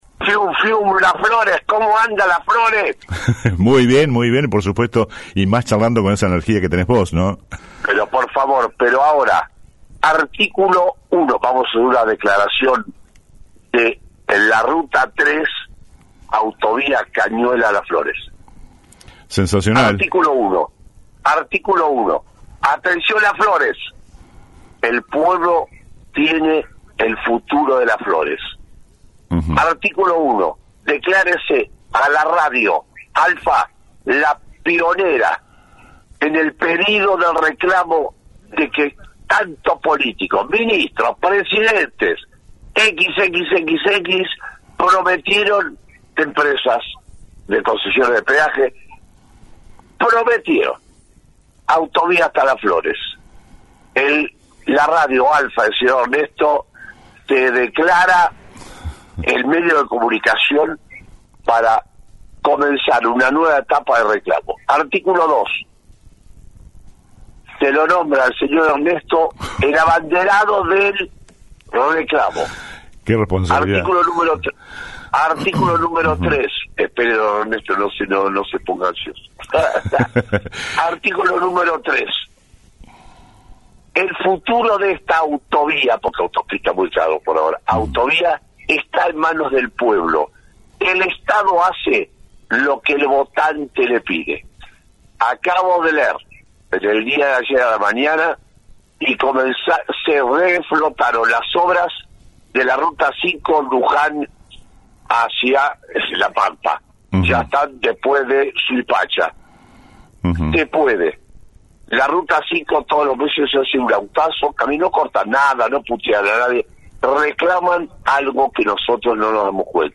El influencer con justa mezcla de jocosidad y seriedad, con décadas de experiencia y millones de kilómetros recorridos por nuestro país, indicó a este medio, concejales y tambien hasta el ejecutivo local, a luchar por el proyecto, ¡ya que el estado hace lo que el votante le pide..” dijo.